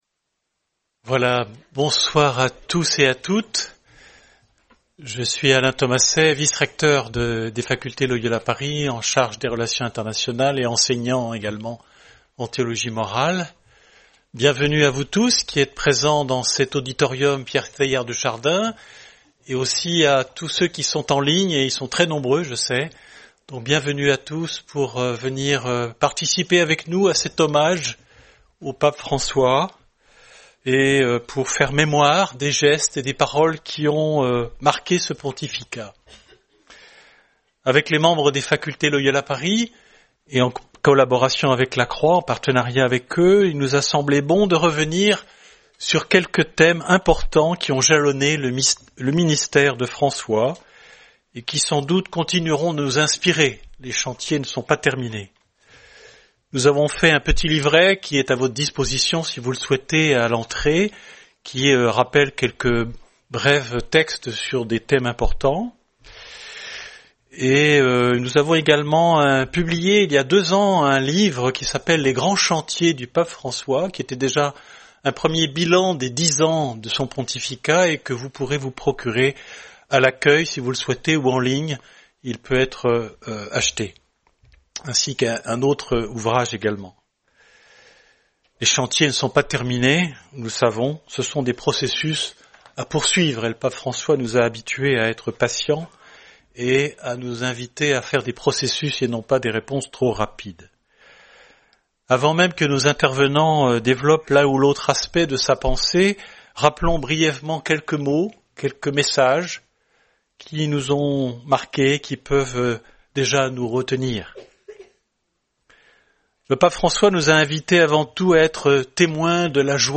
Débat animé